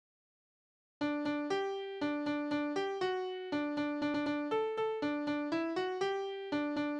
Berufslieder: Seemann
Tonart: G-Dur
Taktart: 3/4
Tonumfang: Quinte
Besetzung: vokal